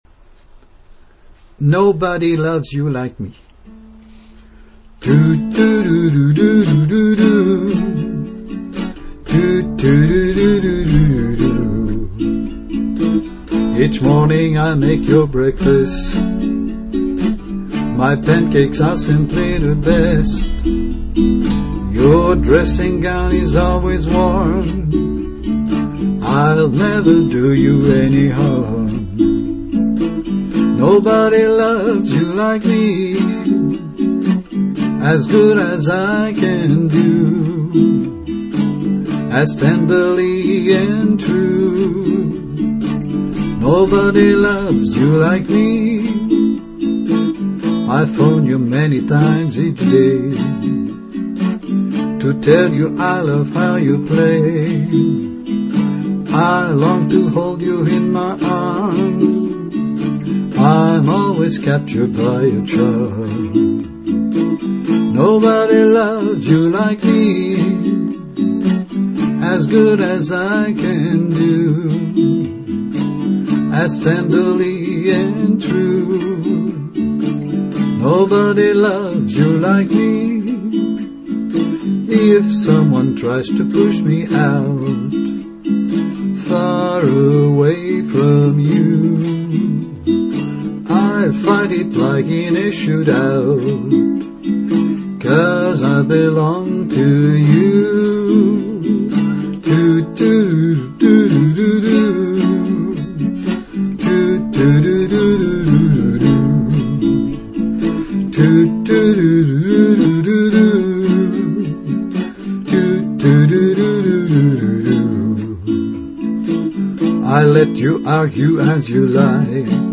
Here is one of my songs.Rough demo: My voice + ukulele
My voice + ukulele https
key of A